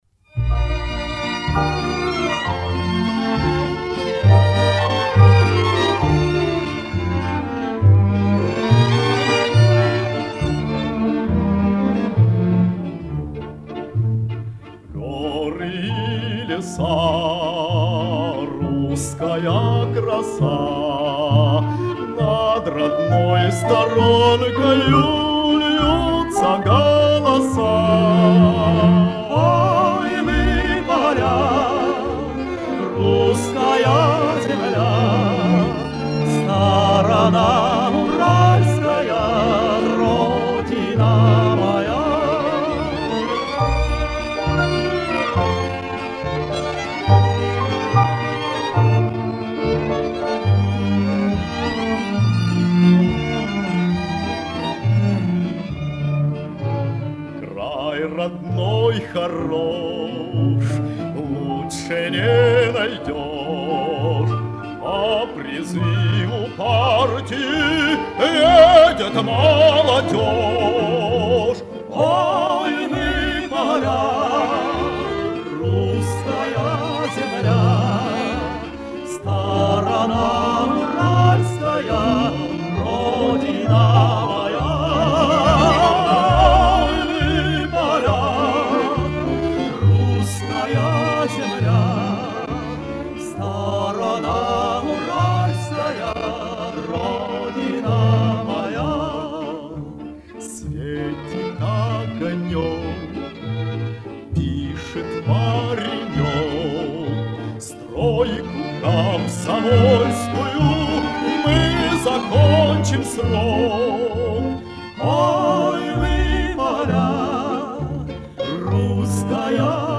Лирико-патриотическая краса